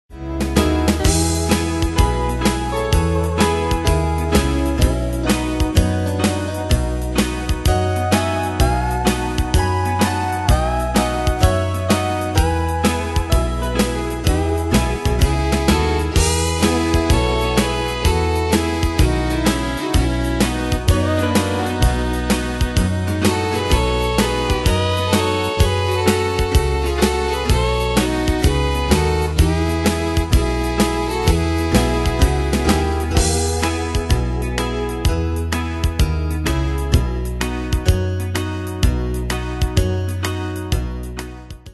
Style: Country Année/Year: 1977 Tempo: 127 Durée/Time: 3.25
Danse/Dance: TwoSteps Cat Id.
Pro Backing Tracks